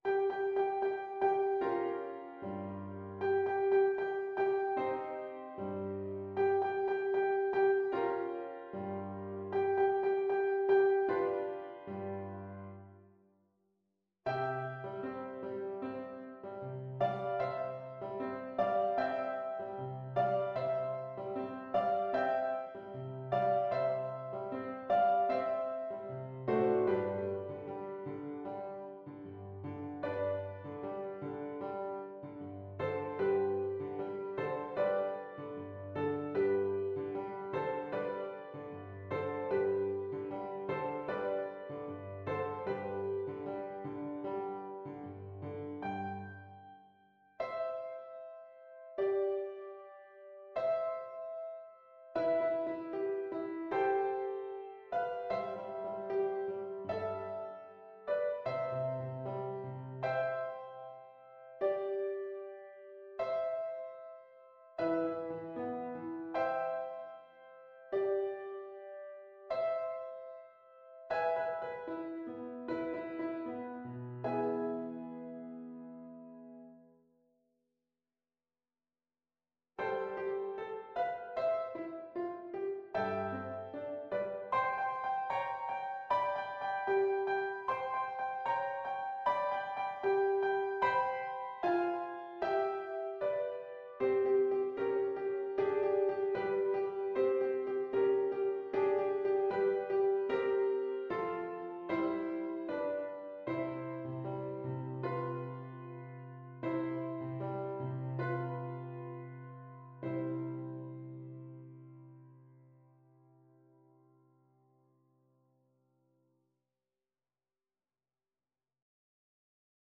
C major (Sounding Pitch) (View more C major Music for Flute )
=76 Allegretto lusinghiero =104
Classical (View more Classical Flute Music)